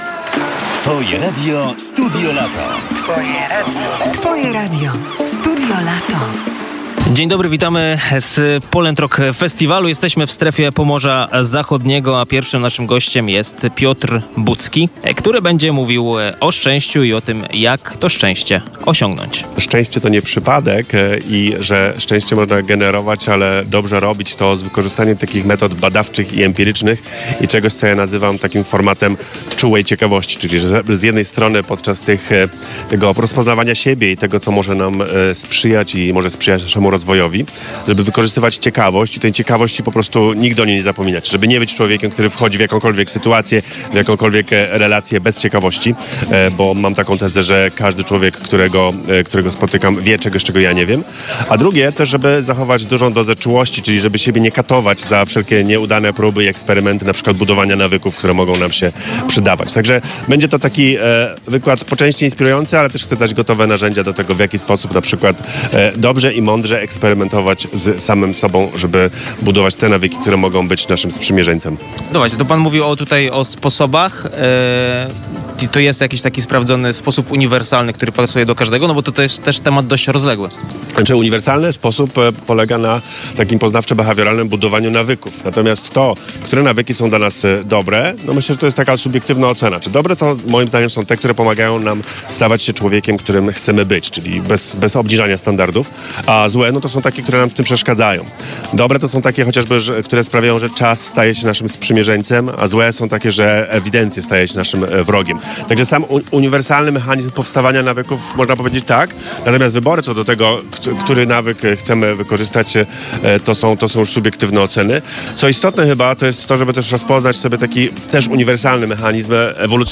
Publikujemy wywiady, nagrane przez reporterów Twojego Radia bezpośrednio w Strefie Pomorza Zachodniego.